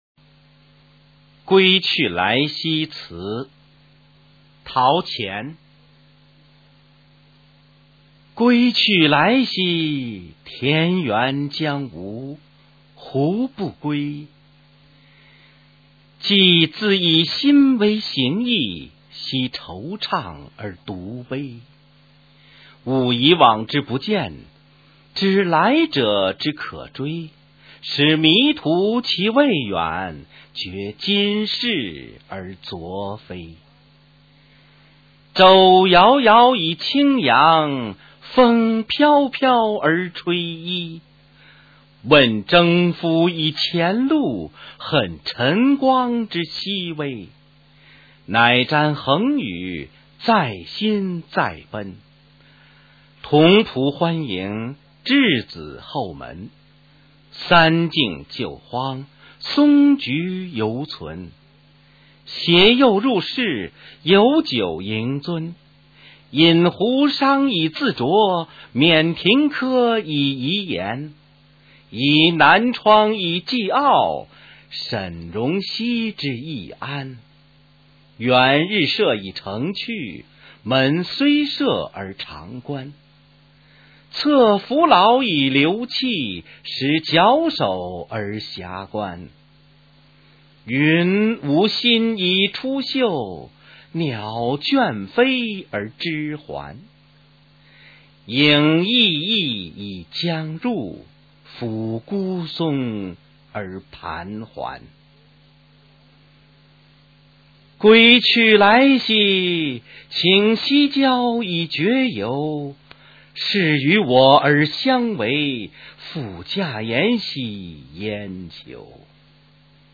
陶渊明《归去来兮辞》原文和译文（含在线朗读）　/ 陶渊明